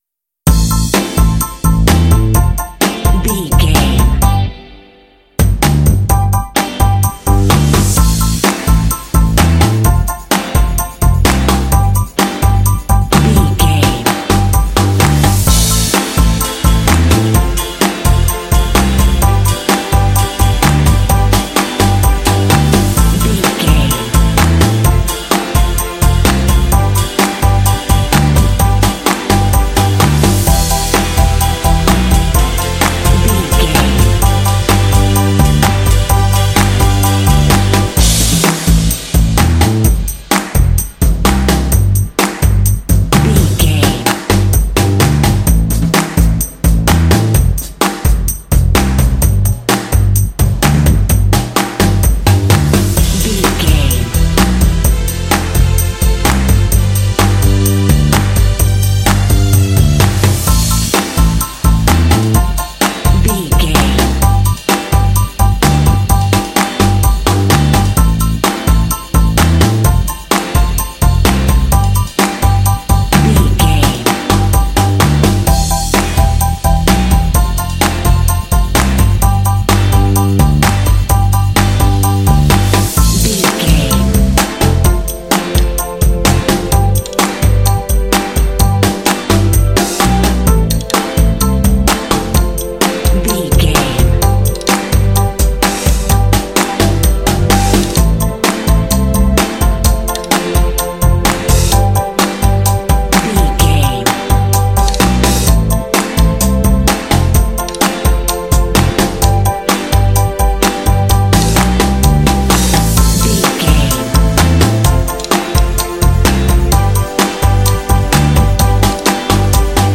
Uplifting
Aeolian/Minor
bouncy
groovy
electric guitar
bass guitar
drums
piano
strings
indie
rock
contemporary underscore